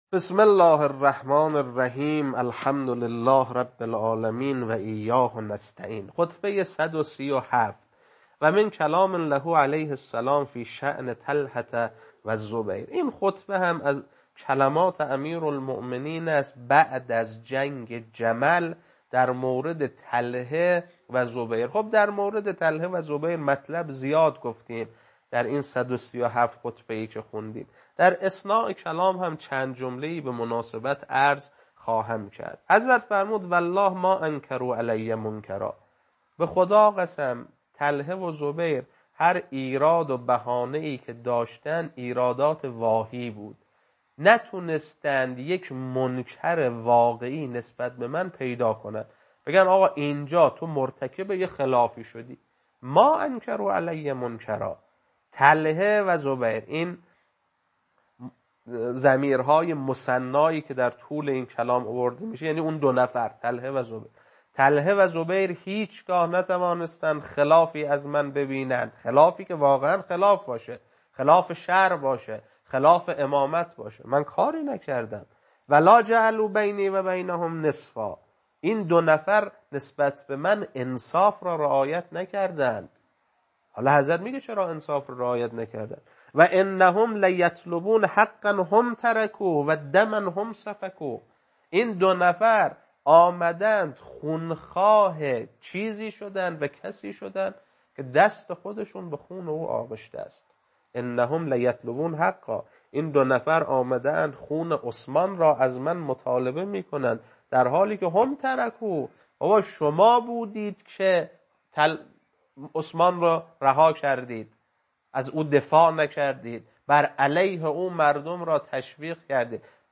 خطبه 137.mp3